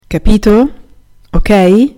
Listen to how it sounds when you say it at the end of a sentence to ask for a confirmation: